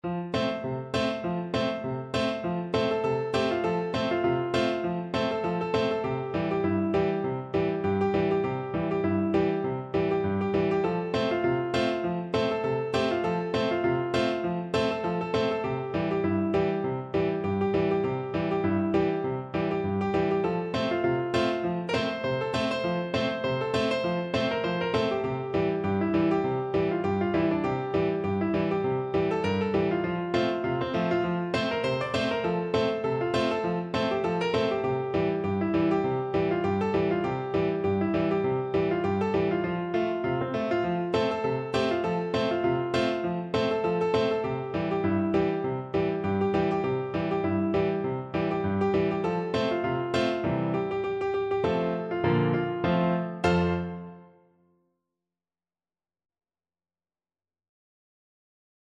No parts available for this pieces as it is for solo piano.
2/2 (View more 2/2 Music)
F major (Sounding Pitch) (View more F major Music for Piano )
Two in a bar with a light swing =c.100
tit_galop_PNO.mp3